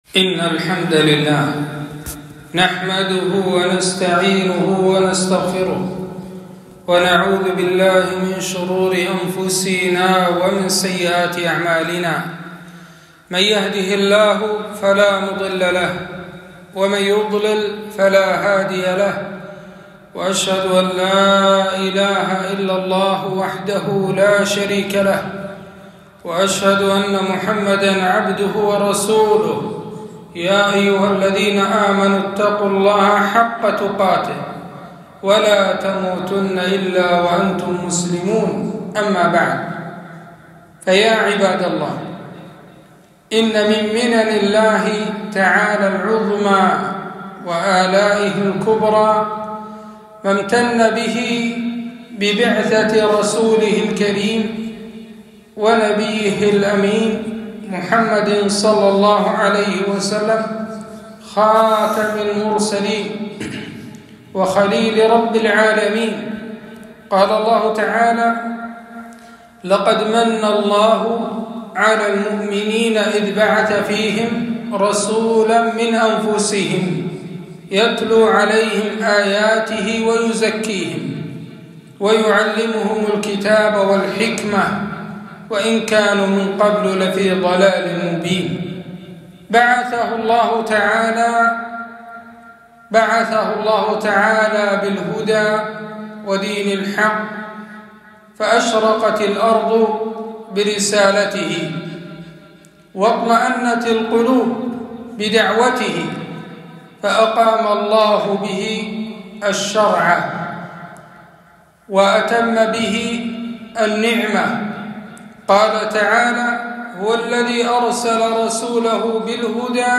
خطبة - شمائل النبي ﷺ